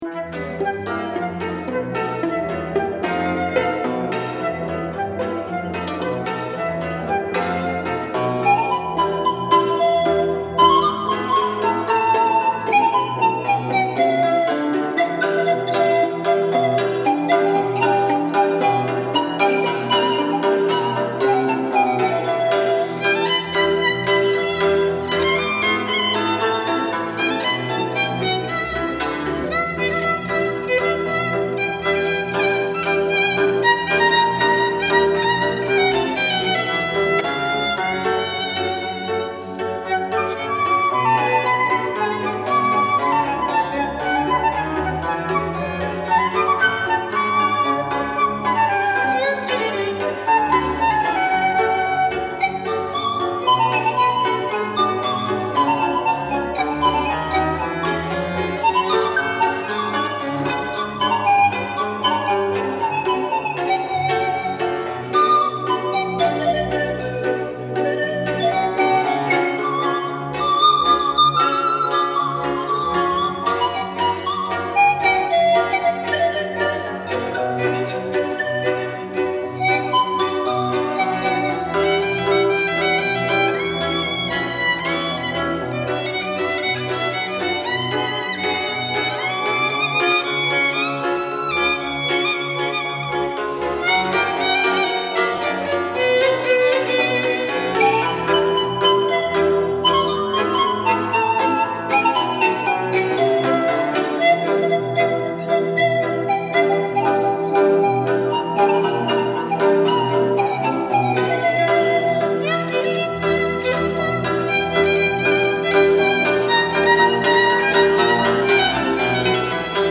Musique de film
violon
flûte traversière
cymbalum